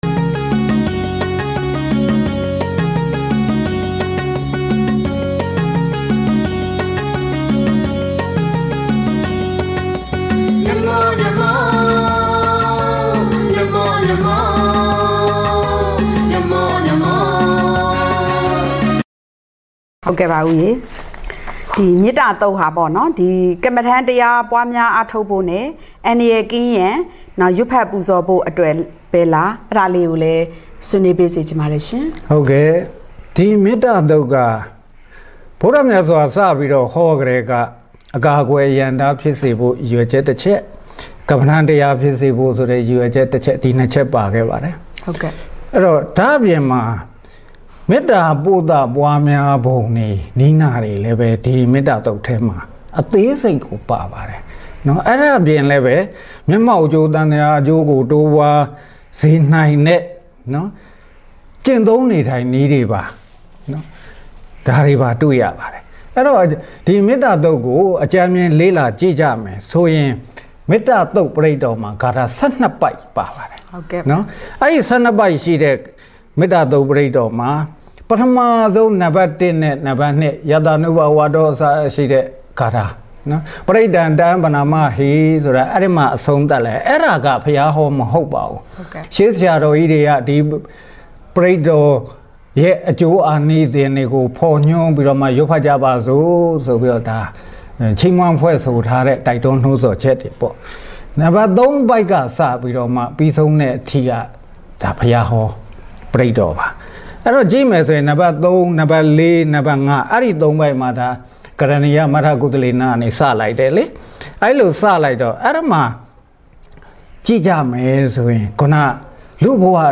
လူထုအ‌ခြေပြုဗဟိုဌာနတွင် ဝါခေါင်လနှင့်မေတ္တာအခါတော်နေ့အကြောင်း အသိပညာပေး အပိုင်း(၂) Talk Show